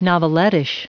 Prononciation du mot novelettish en anglais (fichier audio)